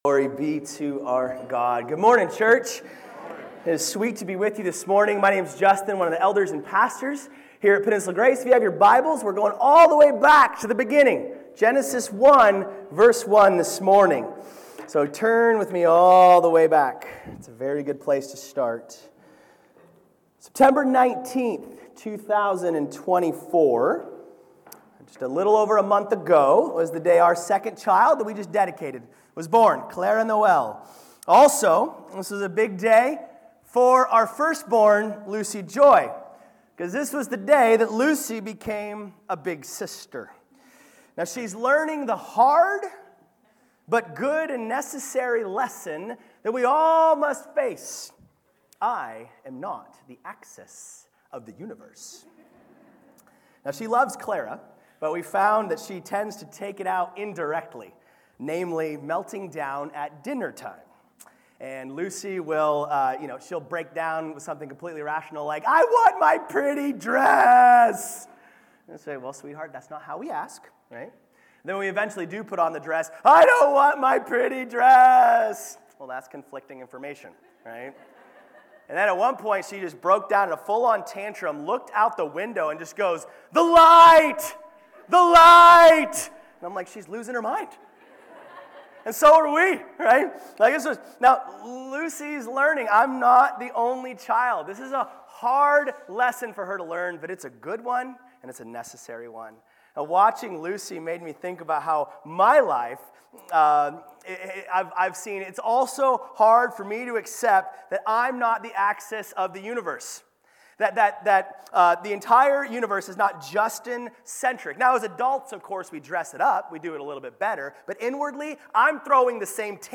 Sermons | Peninsula Grace Church